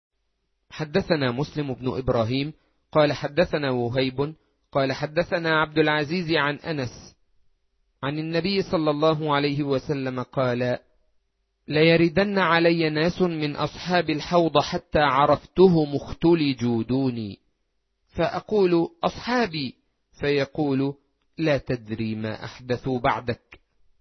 3. الـكتب الناطقة باللغة العربية